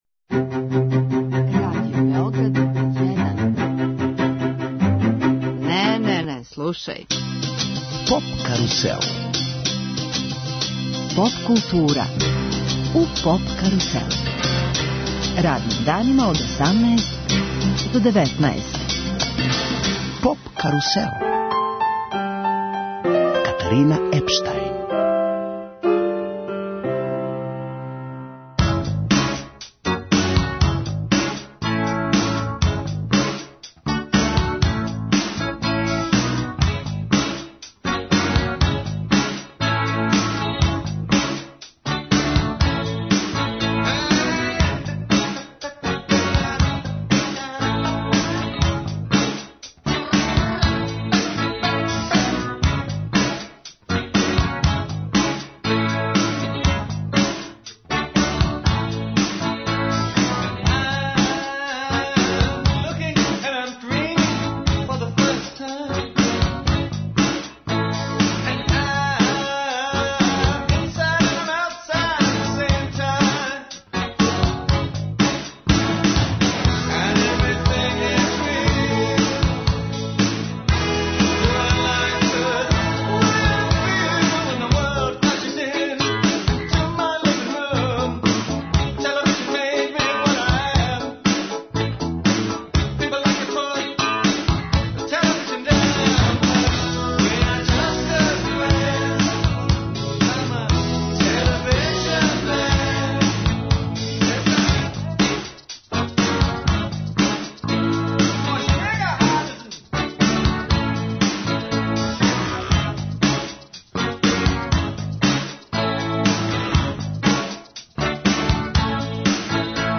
Гости емисије су чланови састава из Ужица, Хаџи продане душе.